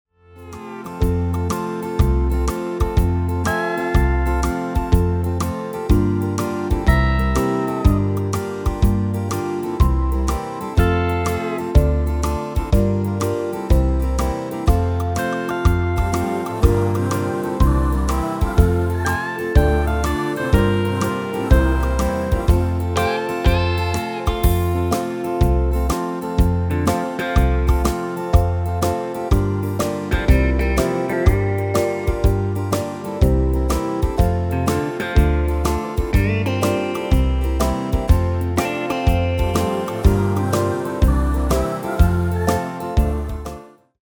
Demo/Koop midifile
Taal uitvoering: Frans
Genre: Pop & Rock Internationaal
Toonsoort: F
- Géén vocal harmony tracks